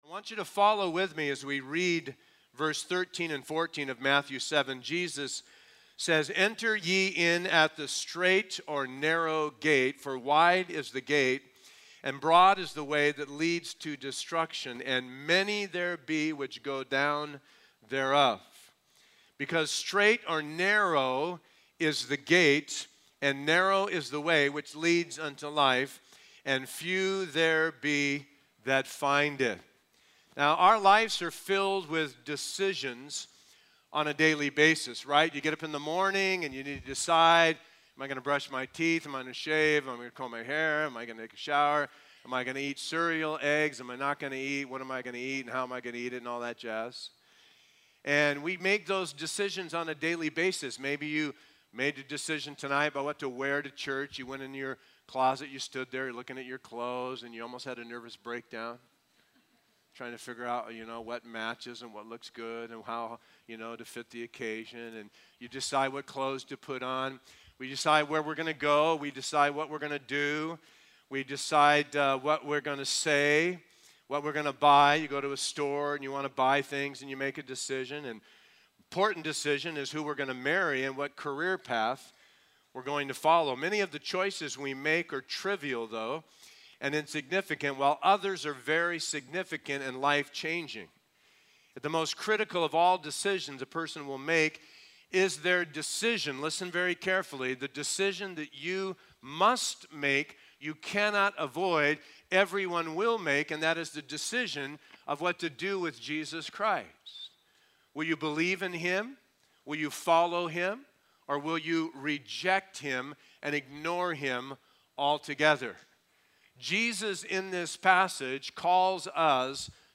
A verse-by-verse expository sermon through Matthew 7:13-14